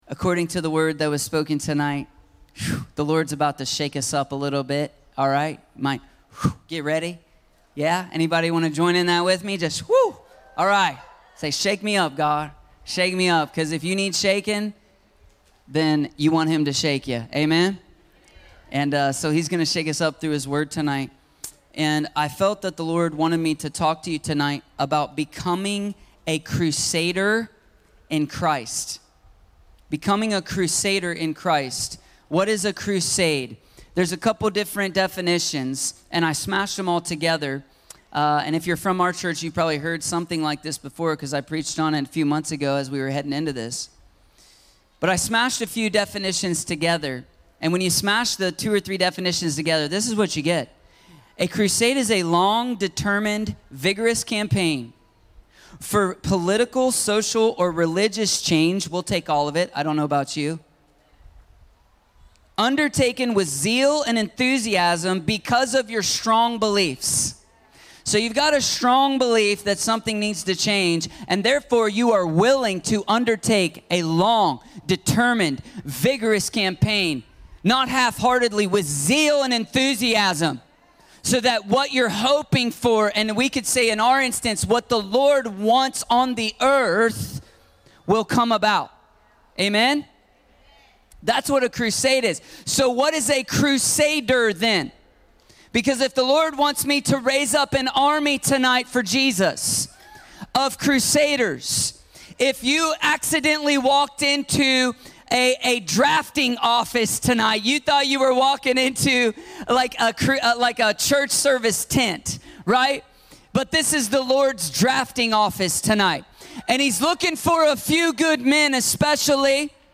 Becoming a Crusader in Christ - Stand Alone Messages ~ Free People Church: AUDIO Sermons Podcast